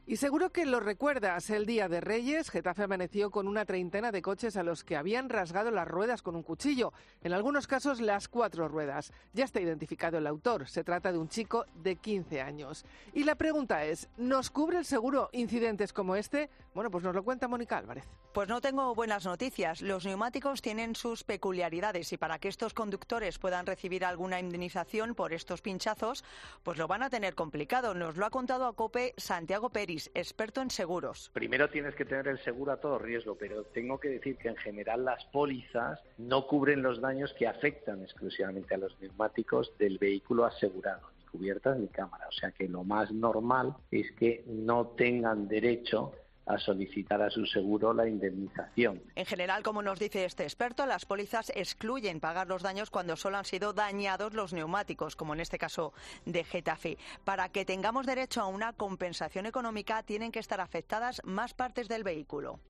El caso de las ruedas es un tanto peculiar, un experto en seguros nos da la respuesta en COPE